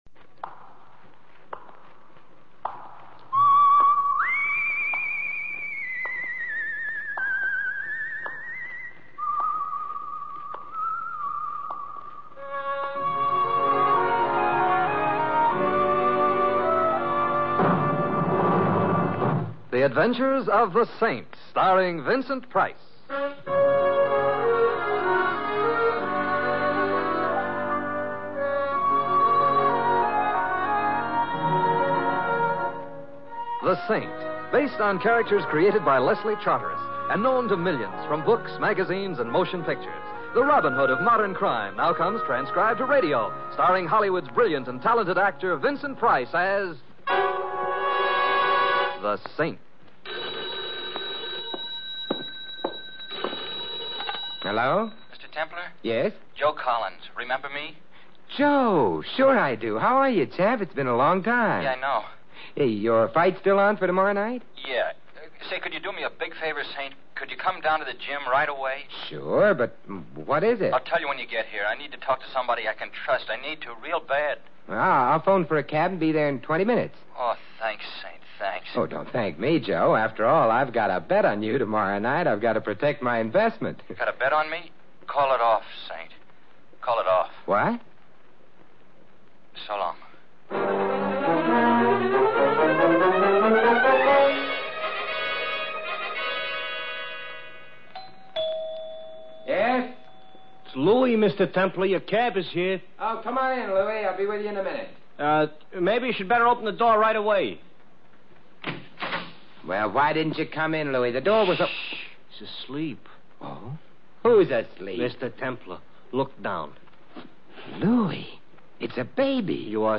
The Saint Radio Program starring Vincent Price